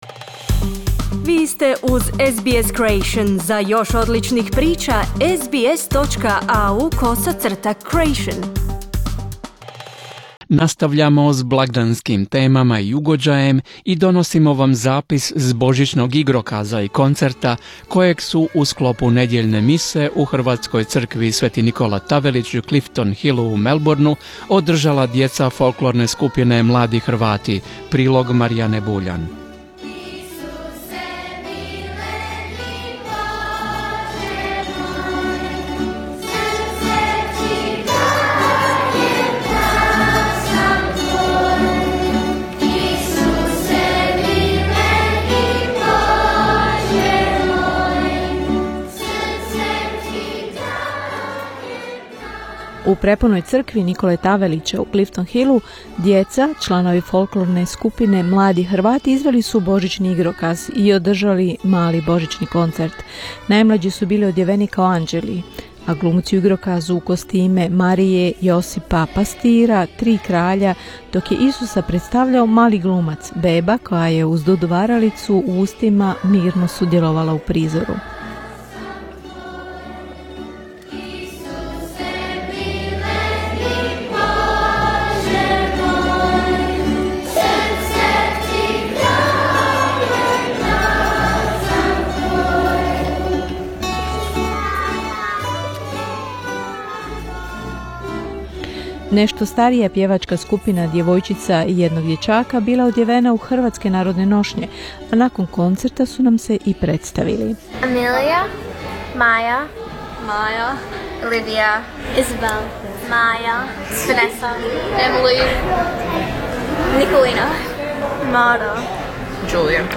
Najmlađi članovi folklorne skupine "Mladi Hrvati" održali su božićni igrokaz i koncert u sklopu nedjeljne mise u crkvi sv.Nikole Tavelića u Clifton Hillu u Melbourneu. Iako mnogi ne razumiju o čemu pjevaju, istinski uživaju u melodiji jezika predaka.